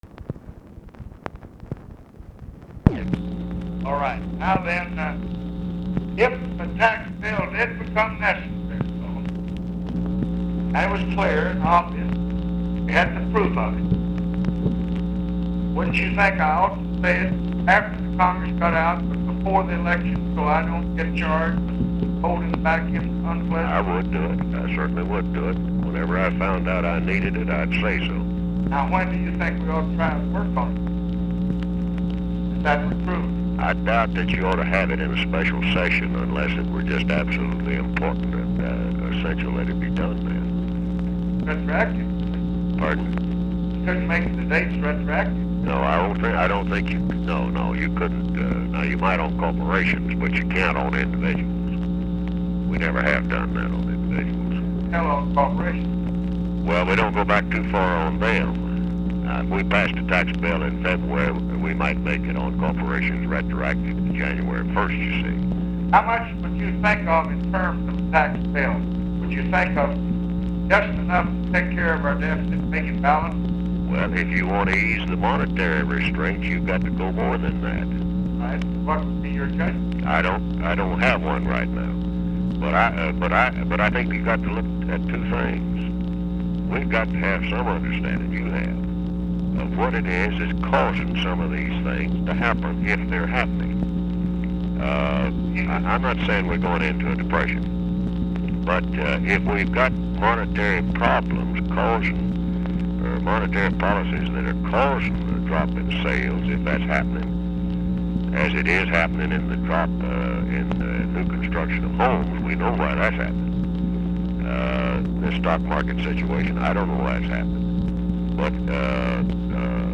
Conversation with WILBUR MILLS, October 6, 1966
Secret White House Tapes